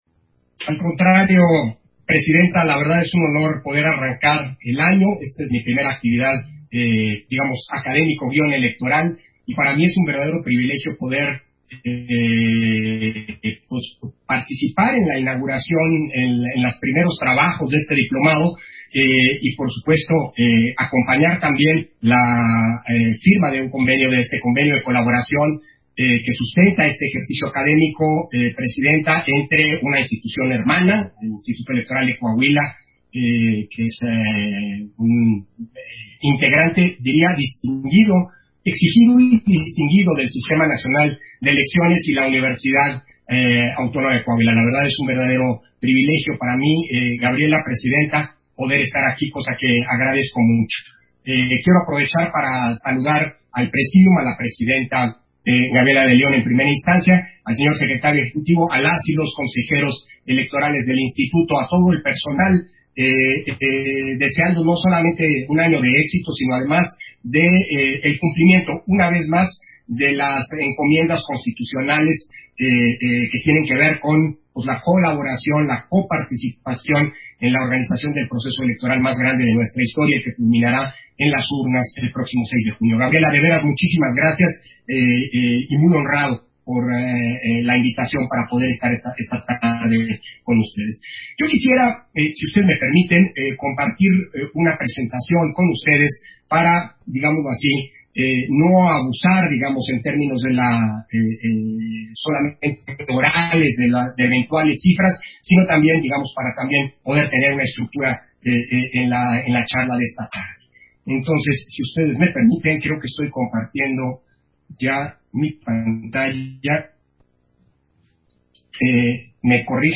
Versión estenográfica de la Conferencia Magistral, Los retos de la democracia mexicana. Elecciones 2021, que ofreció Lorenzo Córdova en el Diplomado de Derecho y Justicia Electoral